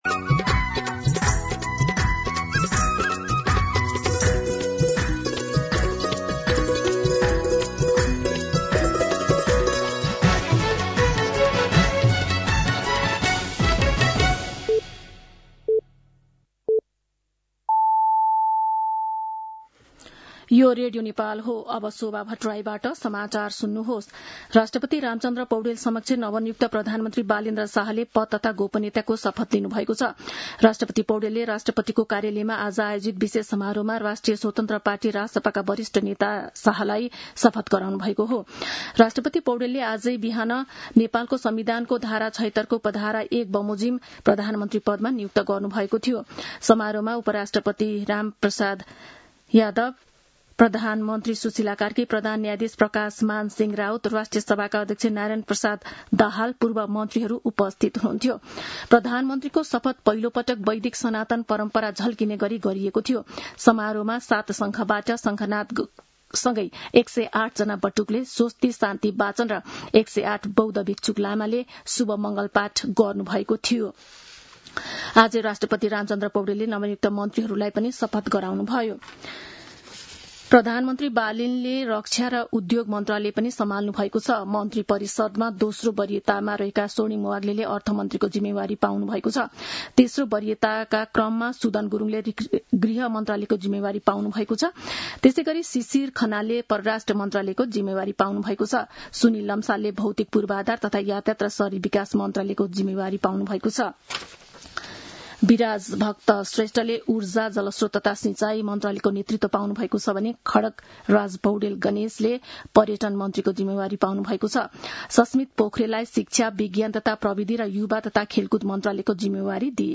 दिउँसो १ बजेको नेपाली समाचार : १३ चैत , २०८२